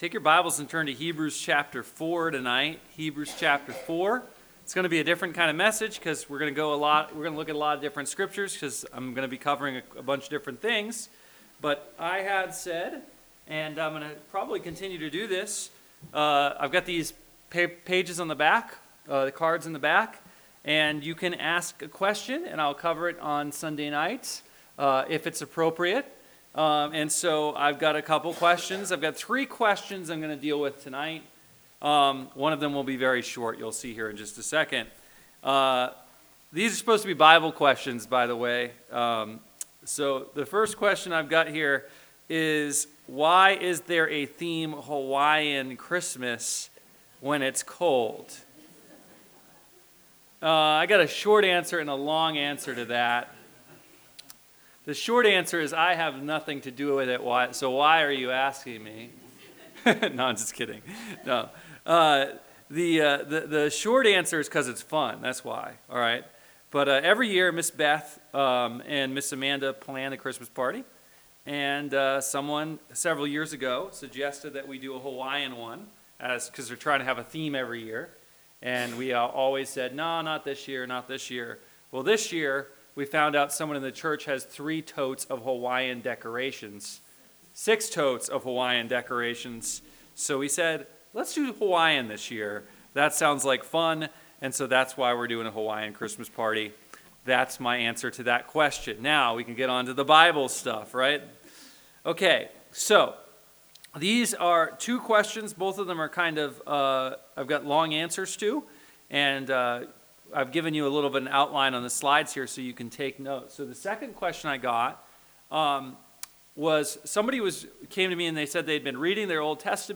Sermons from Bible Baptist Church